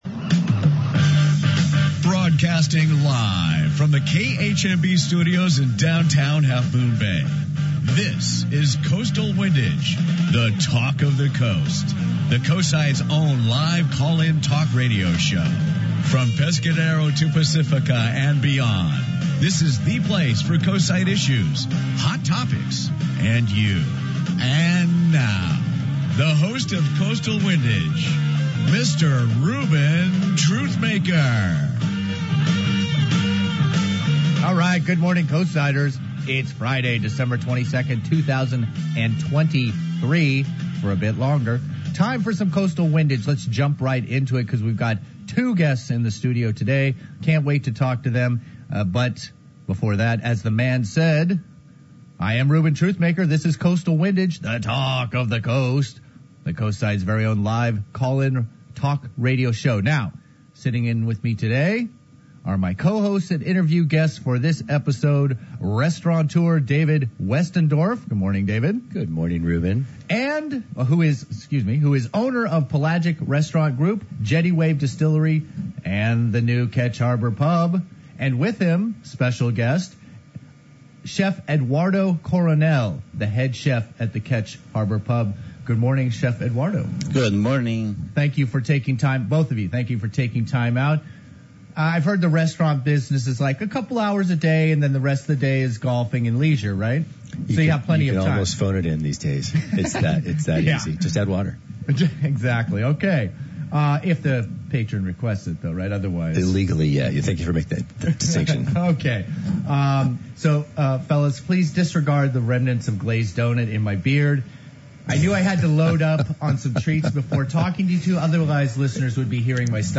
PODCAST. From KHMB’s Coastal Windage recorded on Friday, September 8th, 2023, in the KHMB Studios. The Coastside’s only live, call-in talk radio show, Coastal Windage features Coastside issues, hot topics, and live comments from Coastsiders in real time.